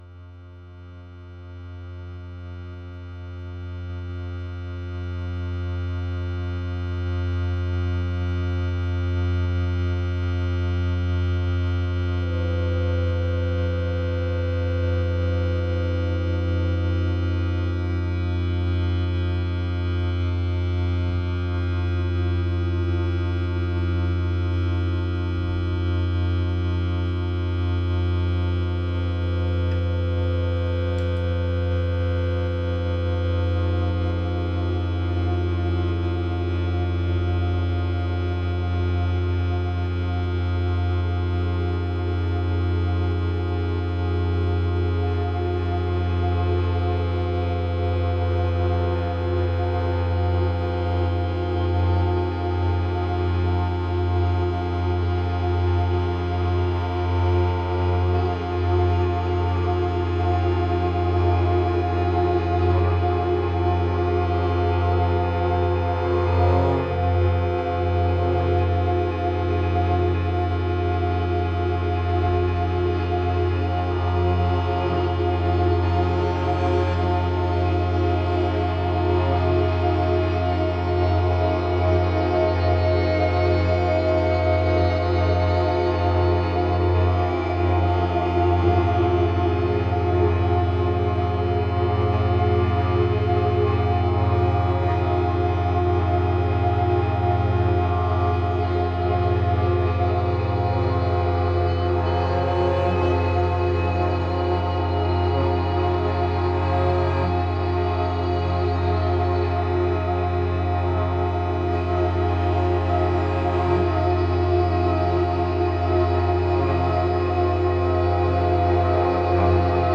終始不穏な音ですがどこか神聖な空気も宿っており、密教的なドローンを聴いているような妙に安らげる音でもありますね！